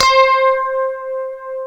L-A   GUITAR 1.wav